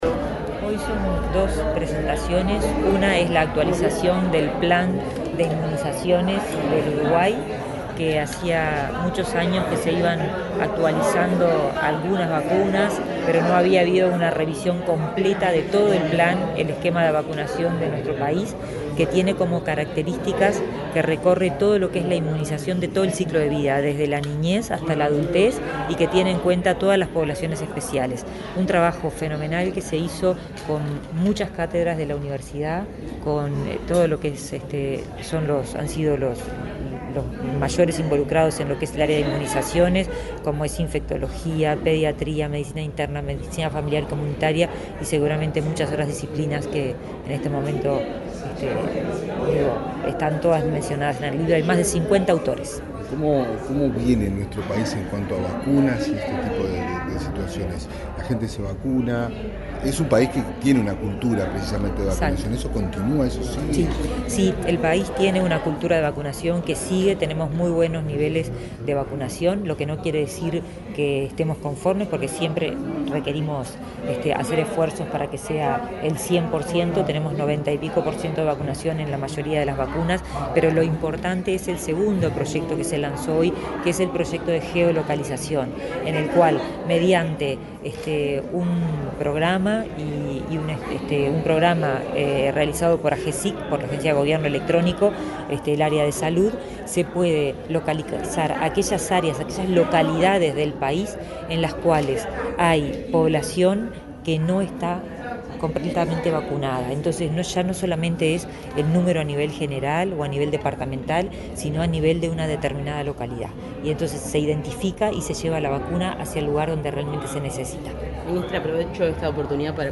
Declaraciones de la ministra de Salud Pública, karina Rando
Declaraciones de la ministra de Salud Pública, karina Rando 16/12/2024 Compartir Facebook X Copiar enlace WhatsApp LinkedIn La ministra de Salud Pública, karina Rando, dialogó con la prensa, luego de encabezar el acto de lanzamiento del manual de inmunizaciones y del monitor de vacunas del Programa Ampliado de Inmunizaciones.